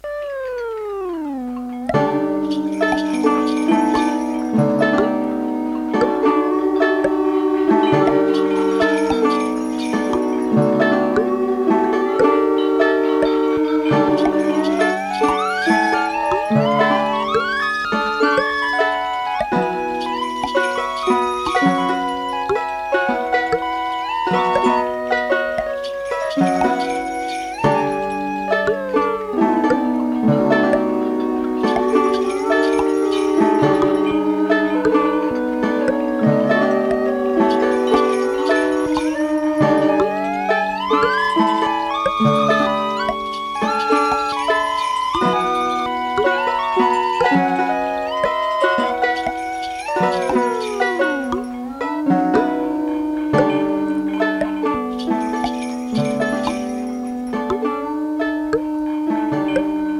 library music